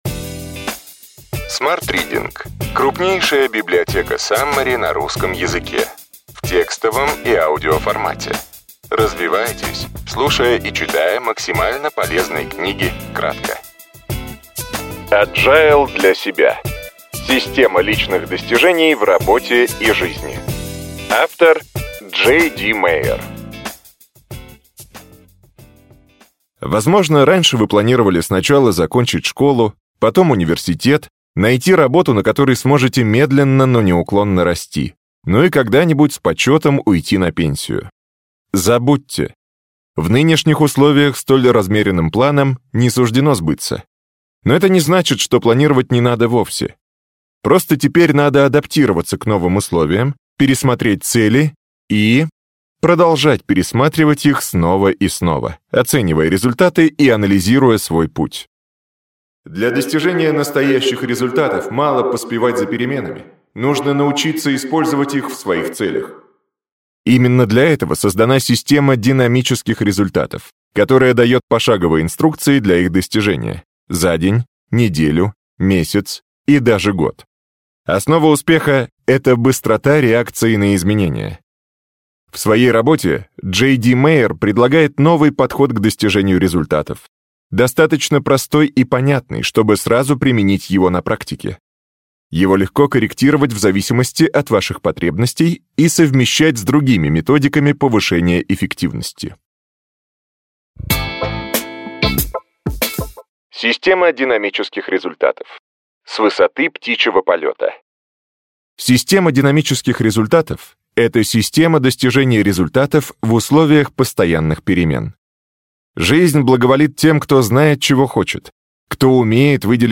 Аудиокнига Agile для себя. Система личных достижений в работе и жизни.